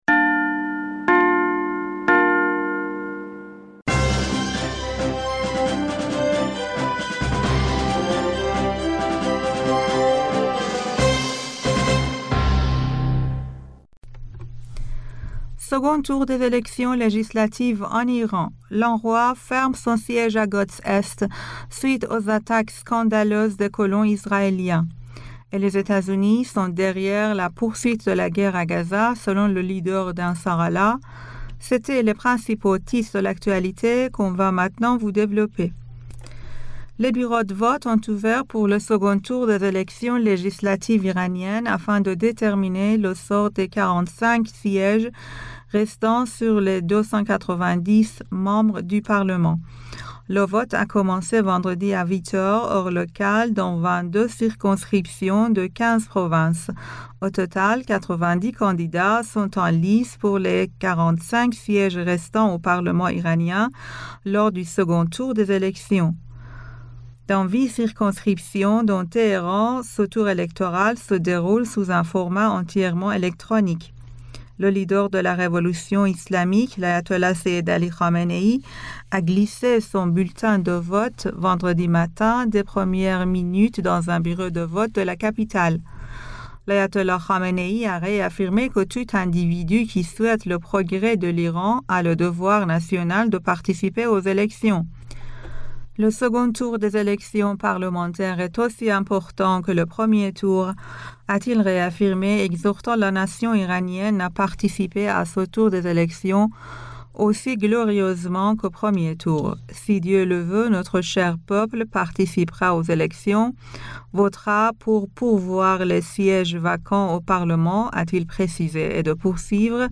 Bulletin d'information du 10 Mai